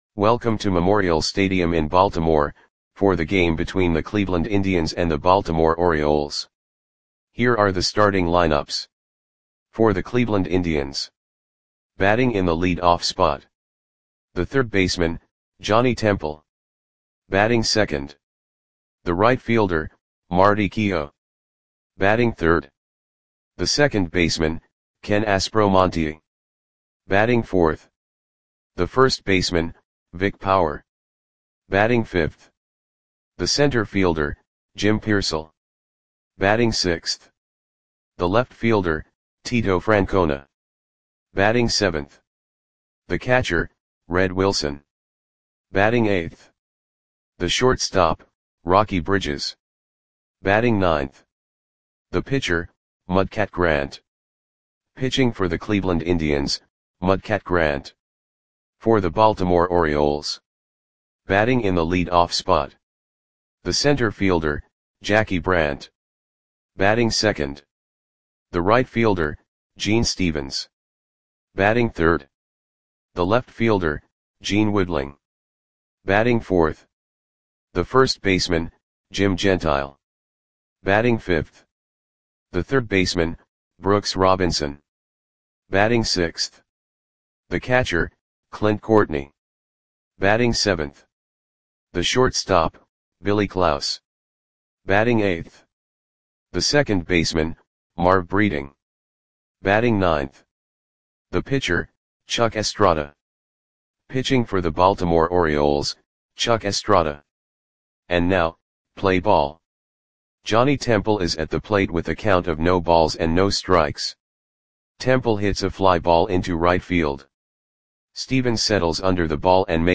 Audio Play-by-Play for Baltimore Orioles on July 30, 1960
Click the button below to listen to the audio play-by-play.